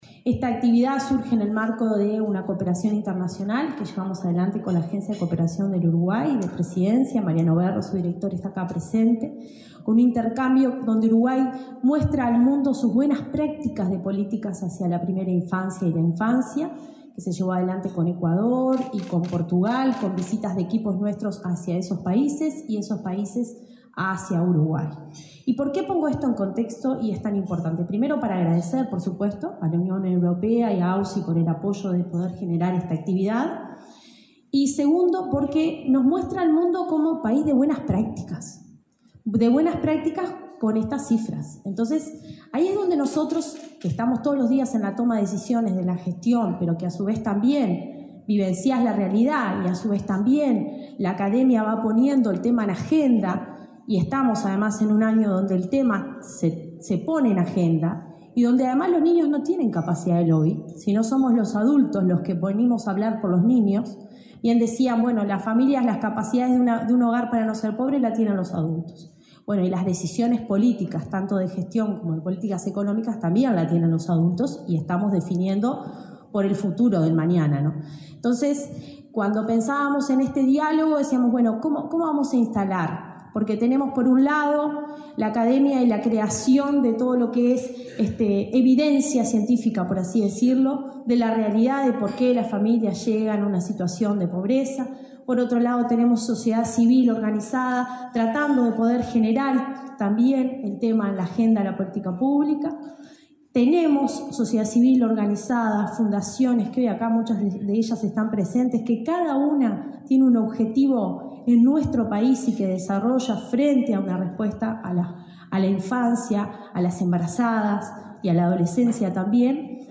Palabras de la directora de Desarrollo Social del Mides, Cecilia Sena
Este lunes 29 en Montevideo, la directora de Desarrollo Social del Ministerio de Desarrollo Social (Mides), Cecilia Sena, participó en el